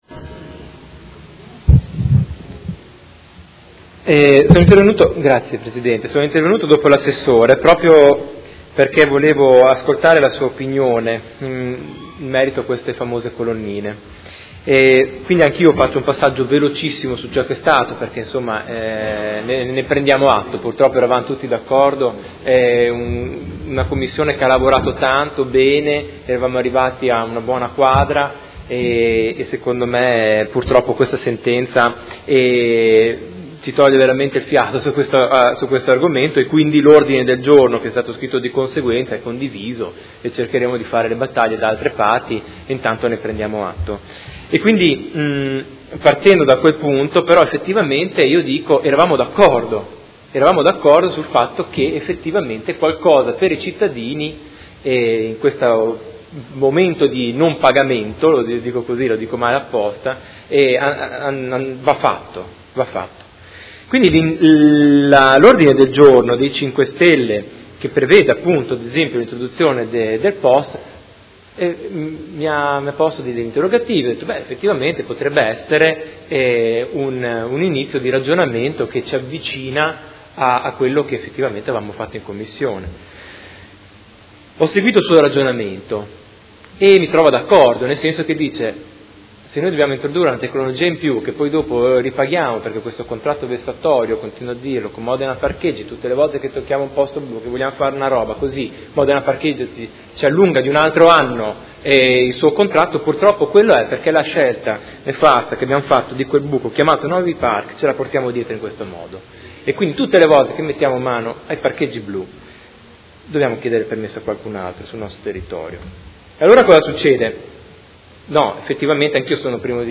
Seduta del 18/05/2017. Dibattito su Proposta di Deliberazione e Ordini del Giorno inerenti aree di parcheggio a pagamento su strada, adeguamento colonnine parcheggi e introduzione del ravvedimento operoso nel codice della strada